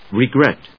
音節re・gret 発音記号・読み方
/rɪgrét(米国英語), rʌˈgret(英国英語)/